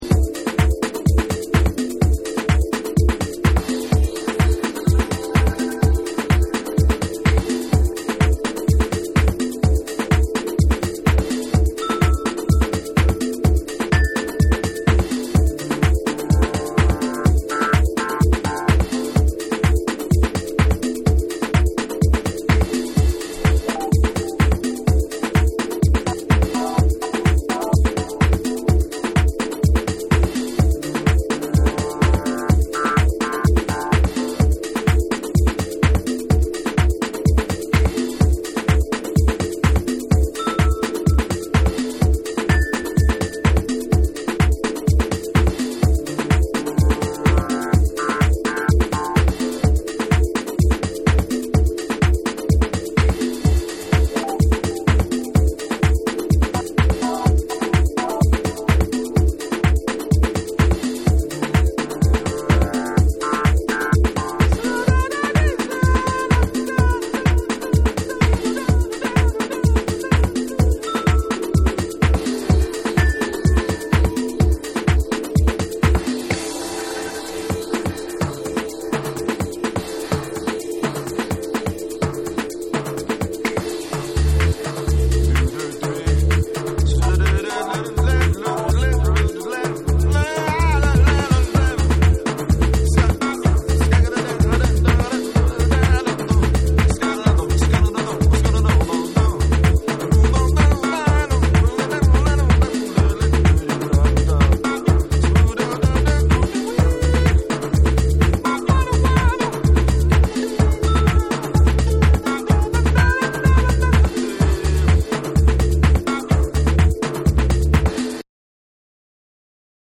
SOUL & FUNK & JAZZ & etc / DANCE CLASSICS / DISCO